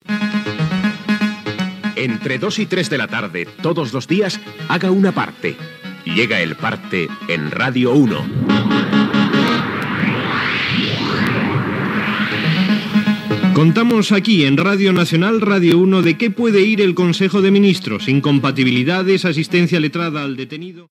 Careta del programa i entrada de l'informatiu parlant del Consell de Ministres espanyol
Informatiu